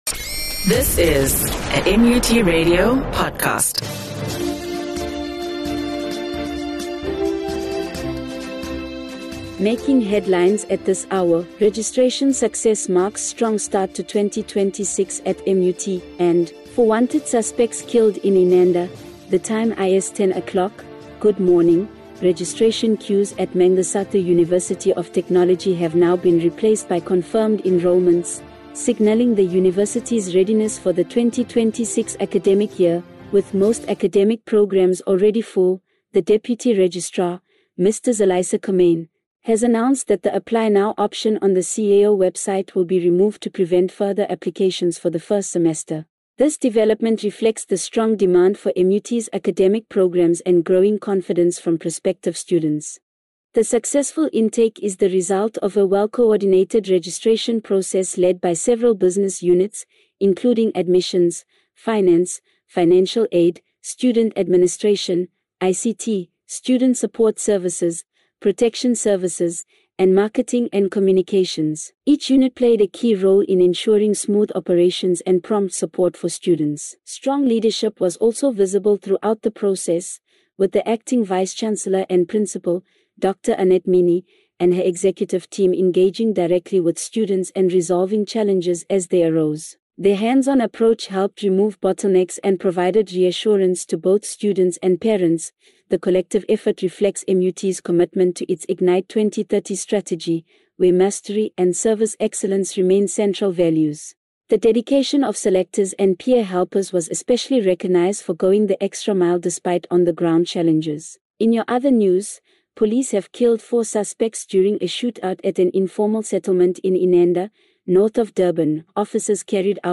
NEWS AND SPORTS